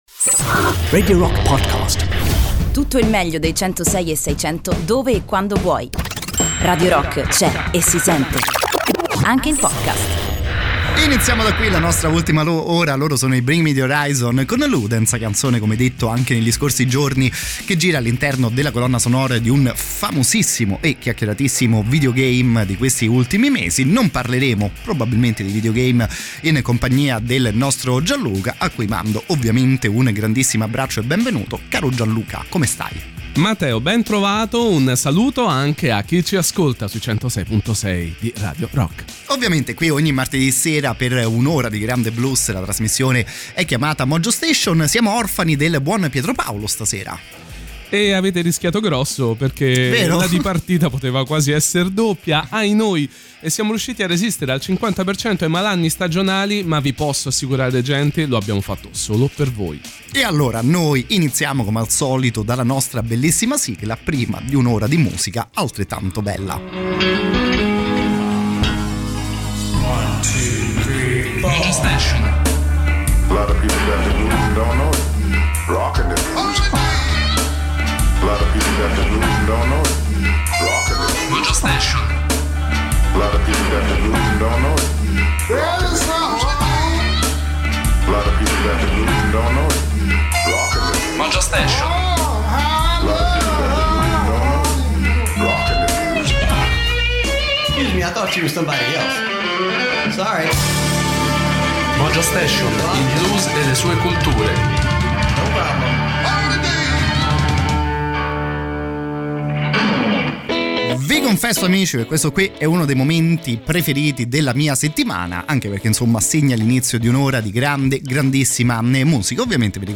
In diretta sui 106e6 di Radio Rock ogni martedì dalle 23:00